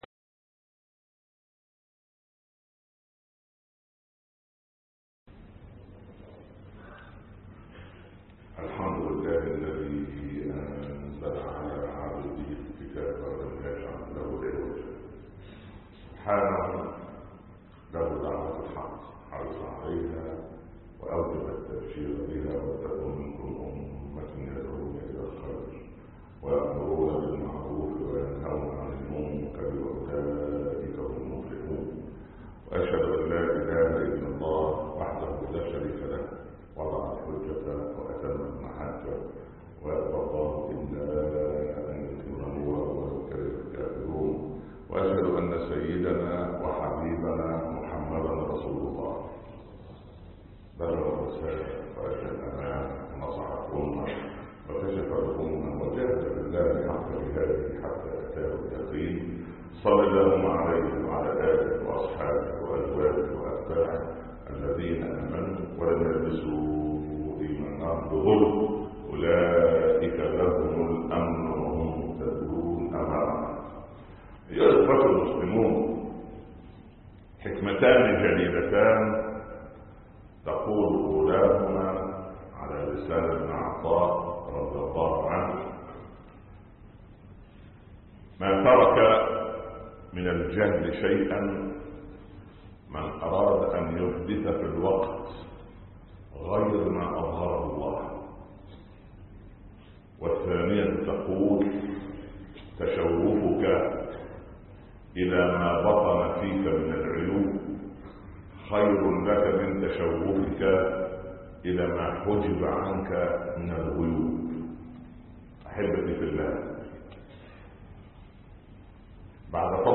خطب الجمعه